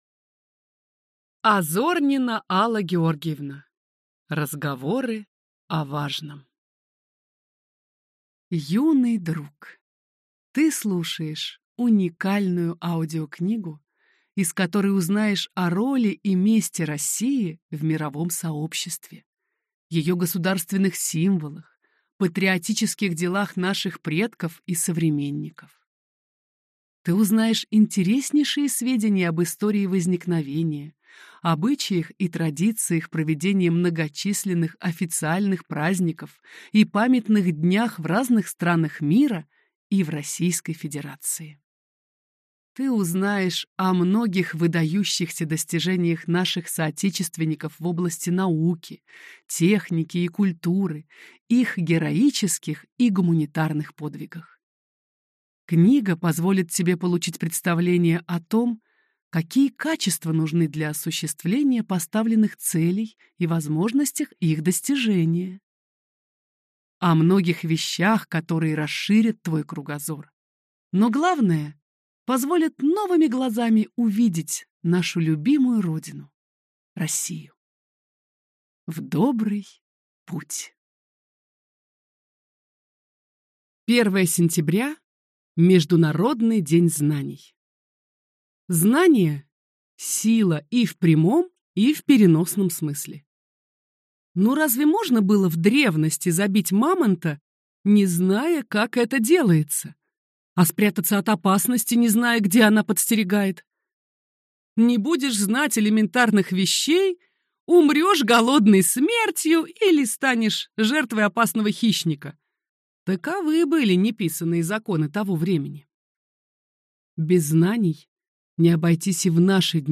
Аудиокнига Разговоры о важном | Библиотека аудиокниг
Прослушать и бесплатно скачать фрагмент аудиокниги